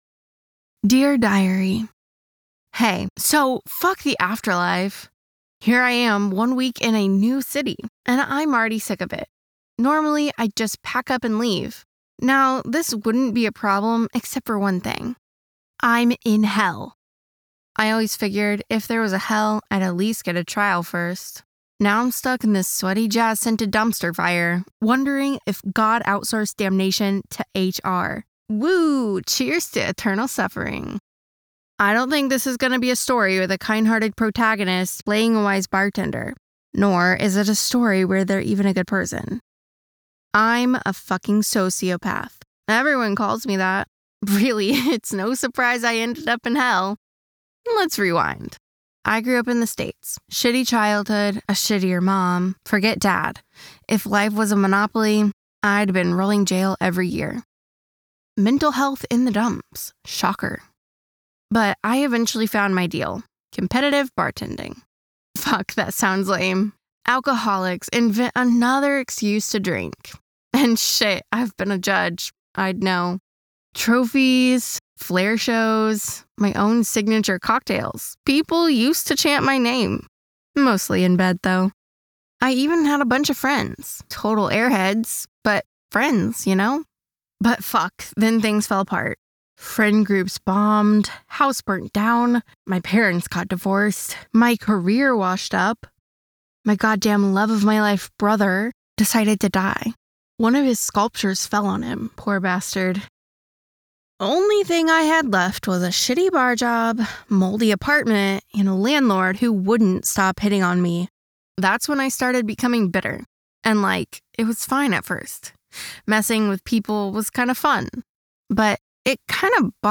audiobook, fiction, fantasy, thriller, romance, sensual, quirky, believable, young adult, scifi, non fiction,
Full-time female American voice actor with soft